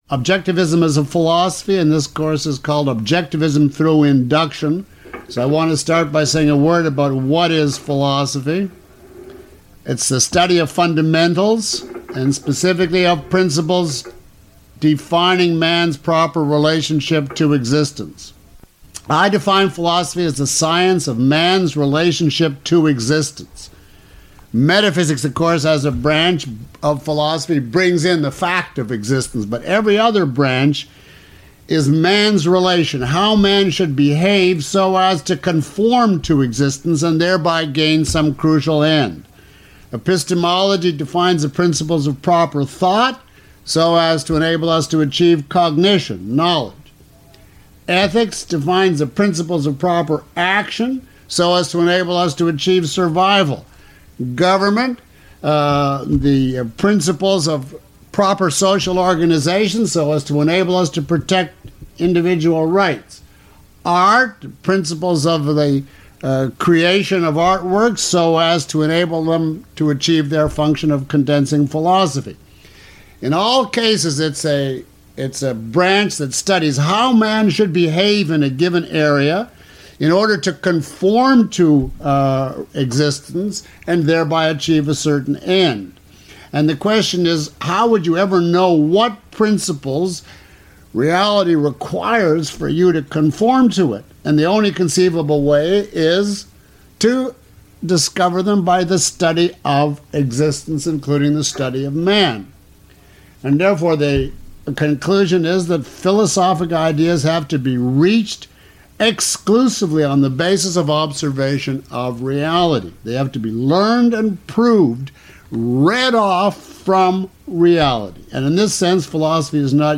Lecture (MP3) Full Course (ZIP) Course Home Lecture Two Questions about this audio?